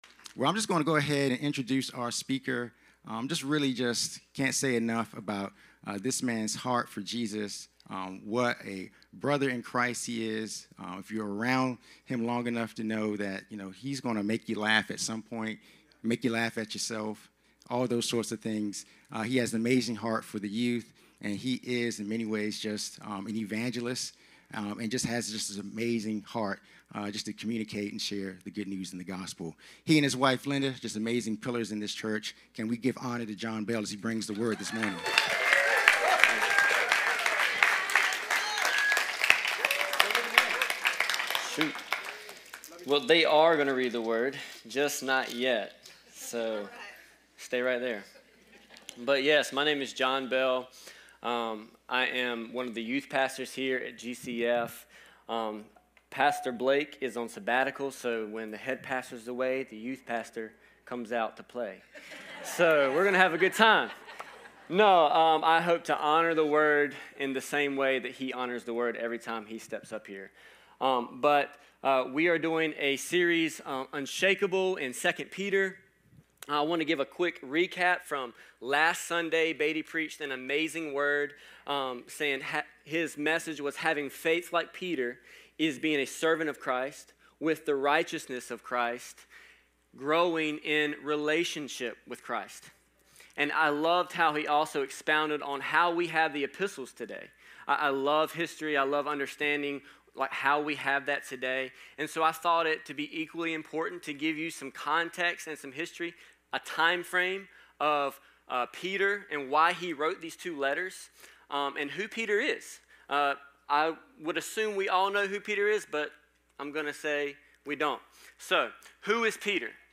Series: Unshakable Service Type: Sunday 10am